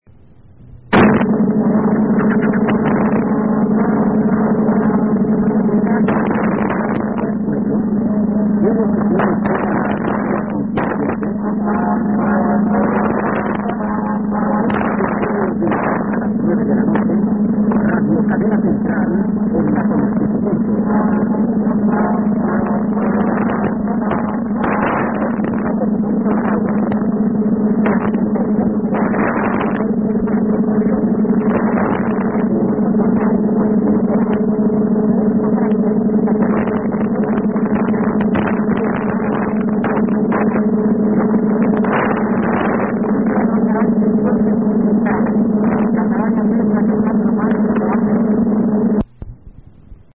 All recordings were made in Santa Barbara, Honduras (SB) using a Yaesu FRG-7 receiver, except for the one marked Danli, which was recorded in Danli, Honduras using an ICF-7600 and the internal loop.